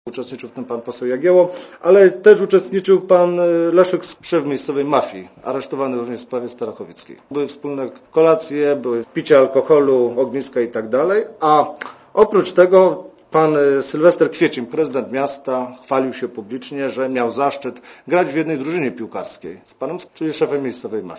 Komentarz audio (84Kb)